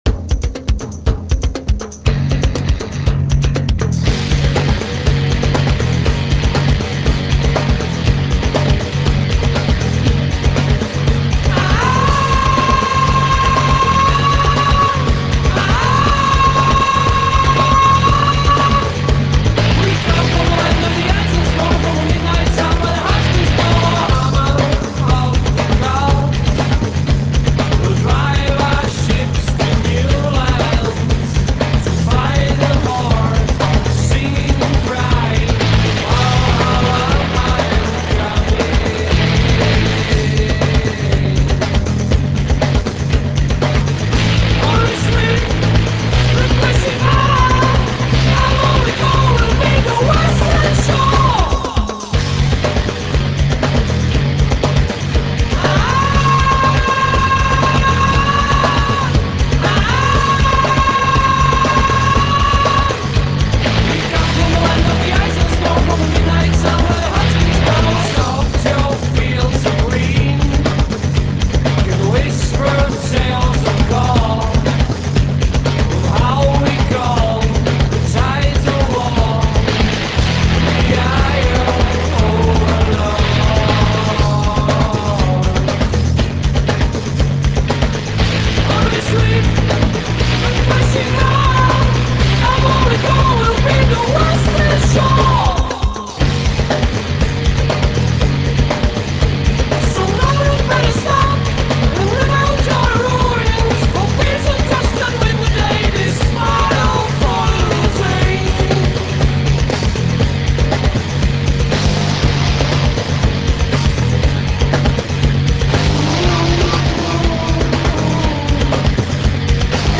264 kB MONO